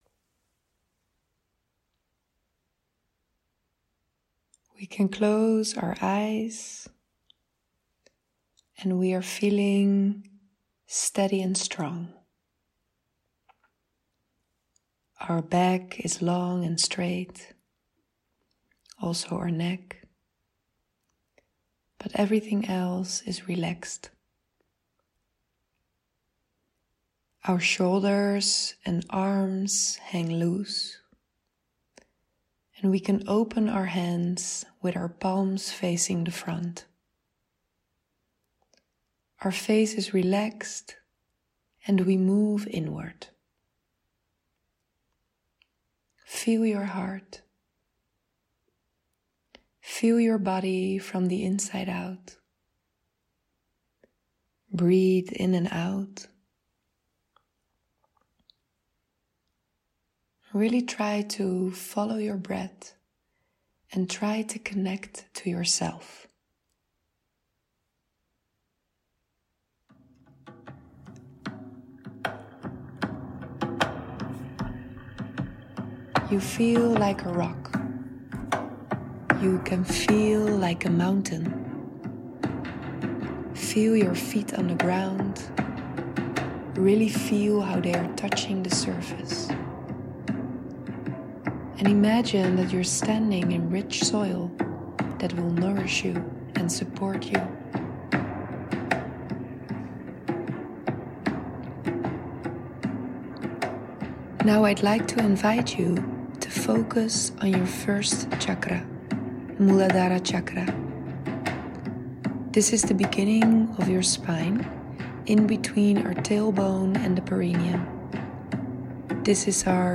Grounding-Meditatie-with-music.mp3